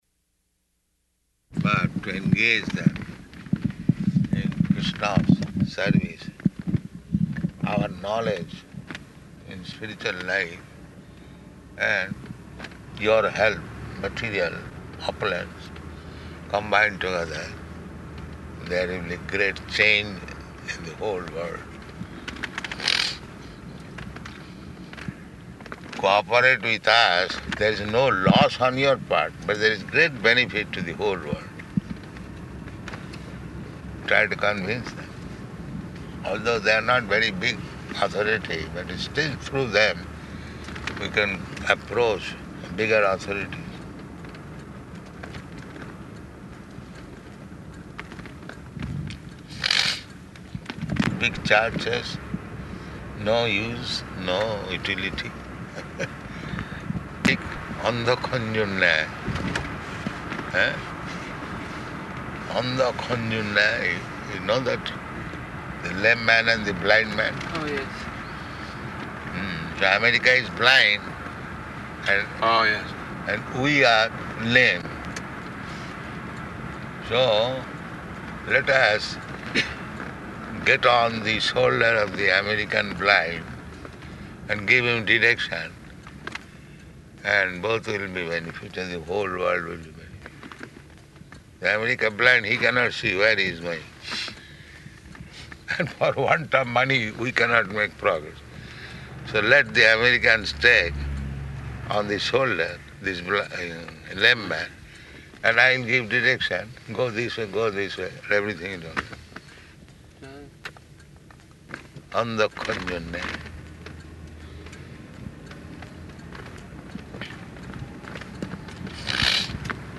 Morning Walk --:-- --:-- Type: Walk Dated: July 8th 1975 Location: Chicago Audio file: 750708MW.CHI.mp3 [in car] Prabhupāda: ...but to engage them in Kṛṣṇa's service.